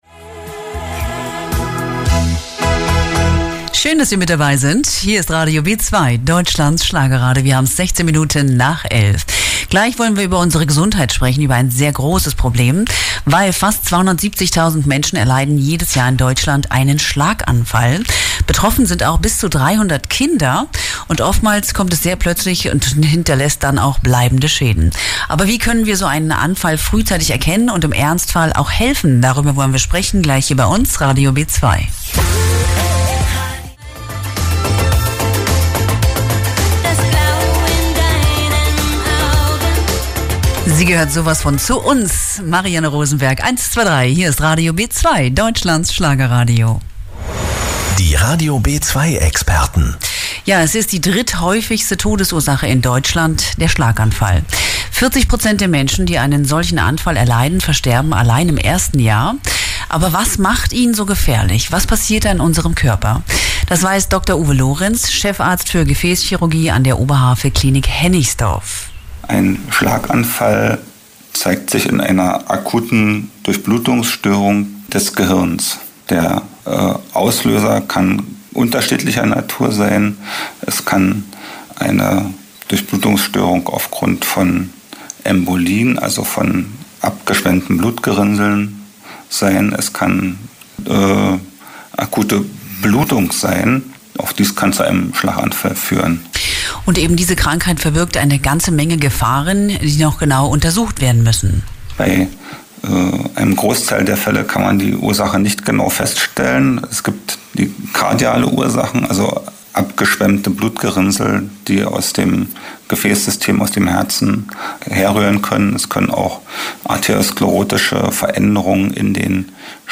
im Interview bei Radio B2.